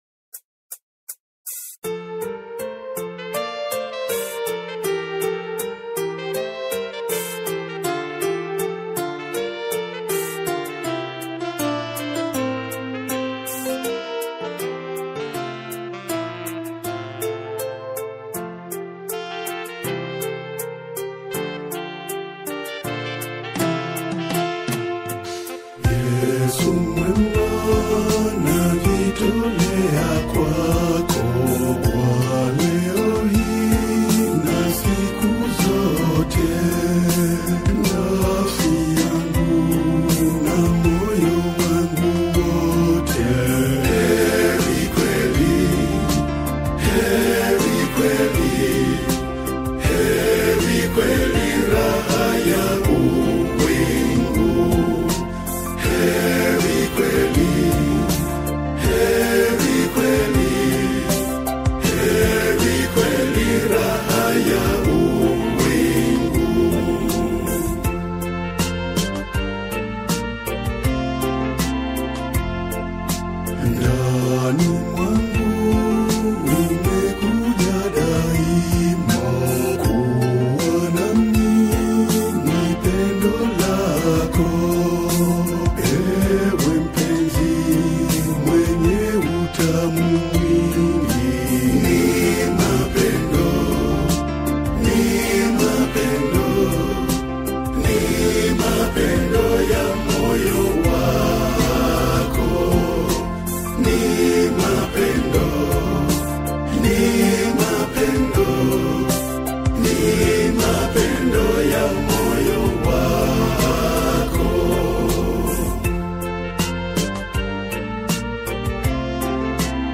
a Tanzanian gospel singer.